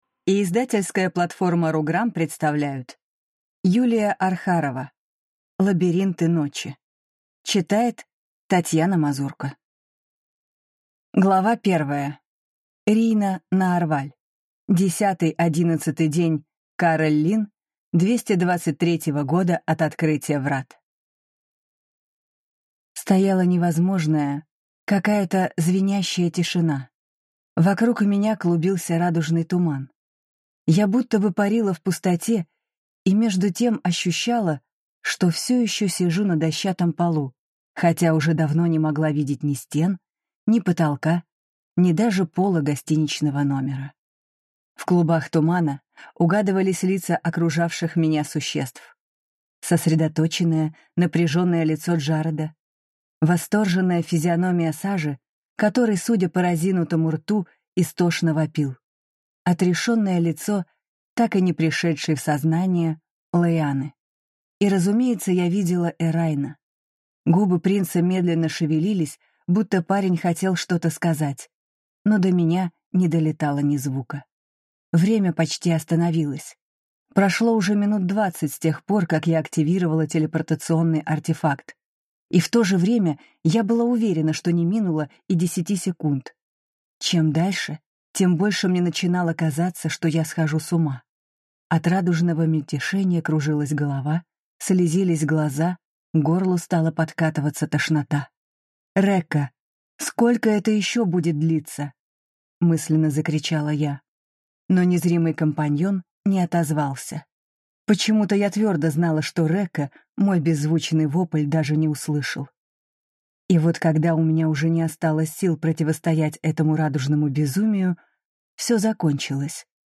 Аудиокнига Лабиринты ночи | Библиотека аудиокниг